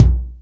Lotsa Kicks(06).wav